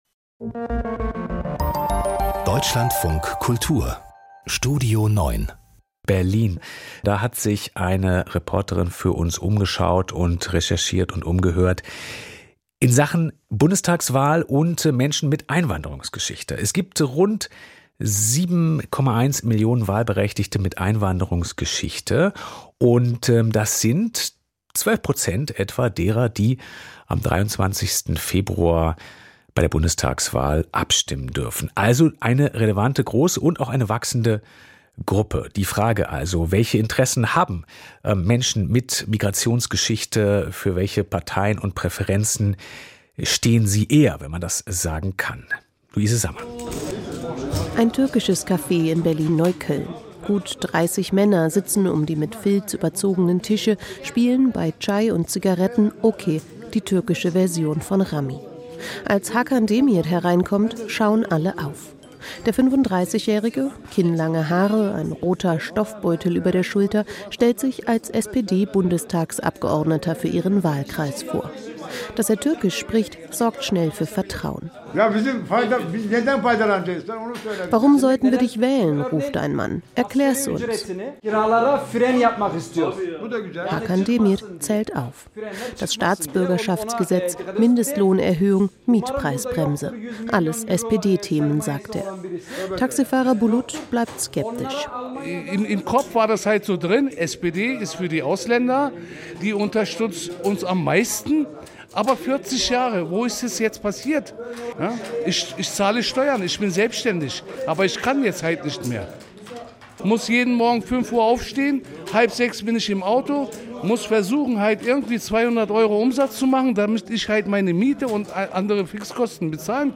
Sie verbindet, dass sie ein geringes Vertrauen in die Lösungskompetenz von Parteien haben. Ein Stimmungsbericht aus Berlin-Neukölln.